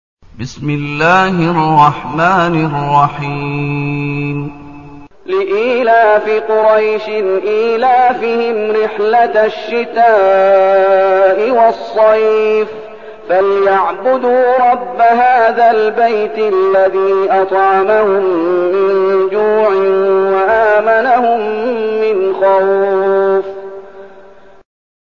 المكان: المسجد النبوي الشيخ: فضيلة الشيخ محمد أيوب فضيلة الشيخ محمد أيوب قريش